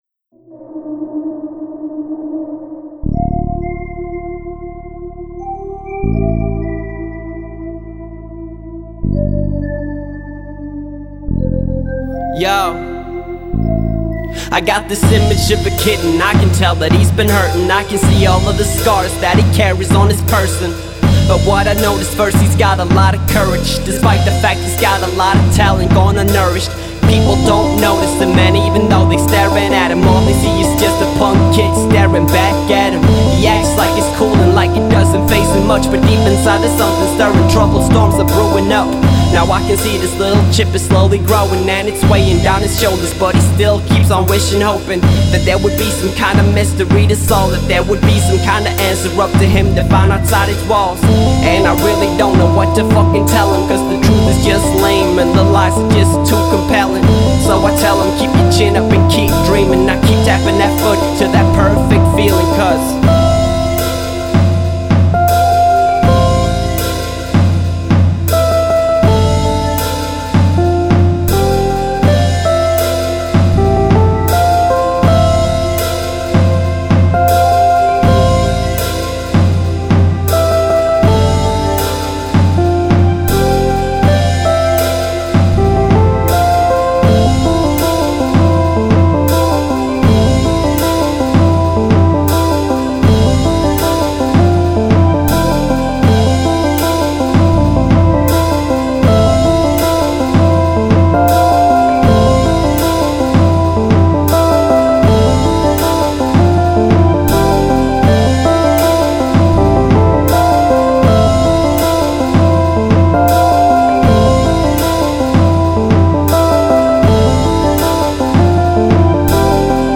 hip-hop mini-epic